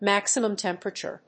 maximum+temperature.mp3